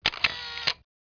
Camera_click.wav